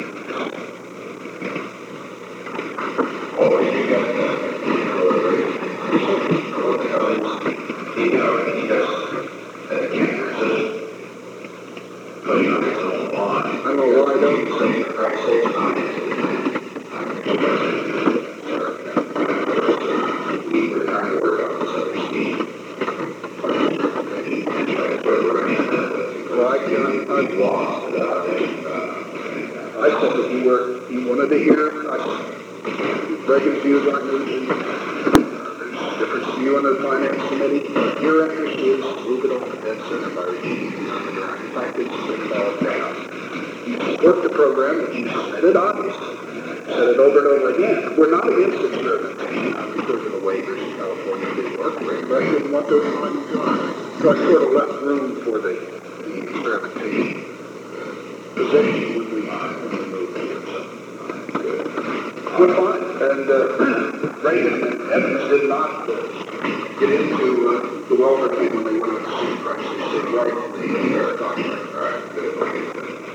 Secret White House Tapes
Conversation No. 663-7
Location: Oval Office
The President met with Ronald L. Ziegler.